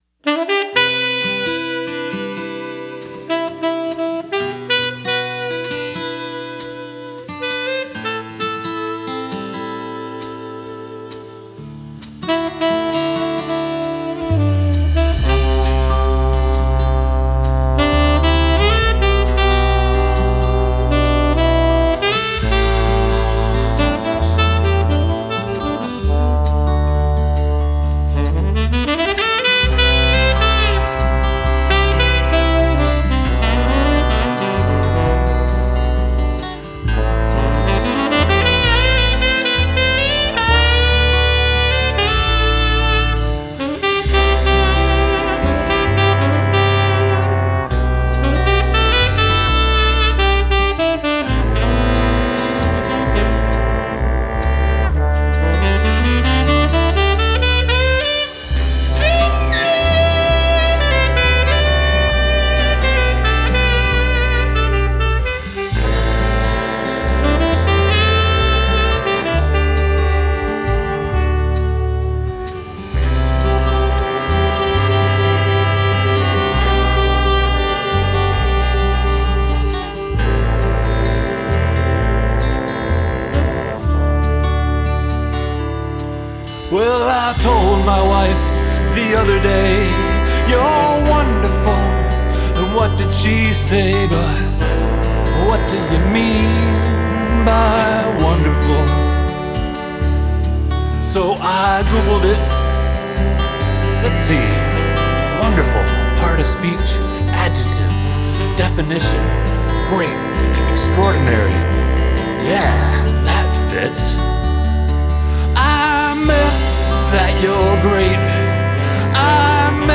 vocals, acoustic guitars [DADGBE]
drums, percussion
electric guitar [standard]
trumpet
trombone
tenor saxophone
baritone saxophone